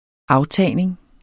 Udtale [ ˈɑwˌtæˀjneŋ ]